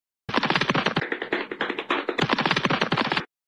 Goofy Ahh Running Sounds Meme